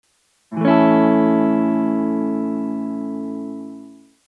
پکیج ریتم و استرام گیتار الکتریک ایرانی
دموی صوتی استرام سریع :
strum-1.mp3